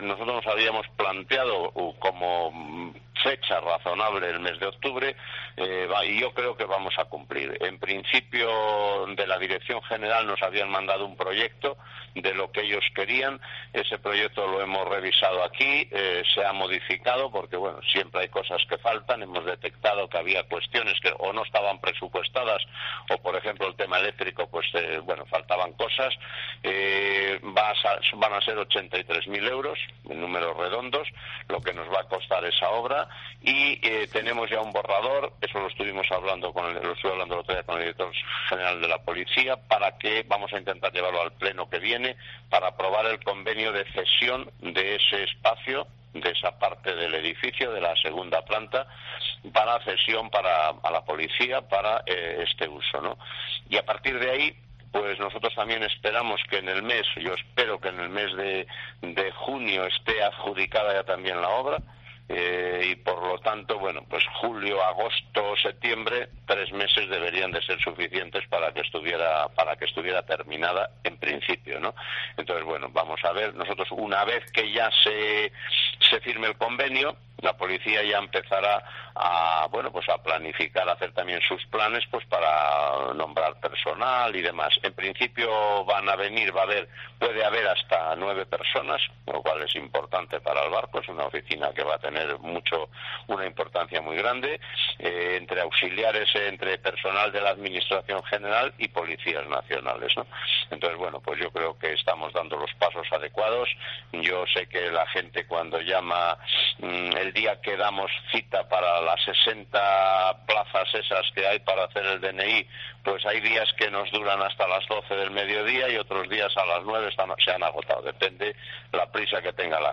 Declaraciones de Alfredo García sobre la futura oficina del DNI de O Barco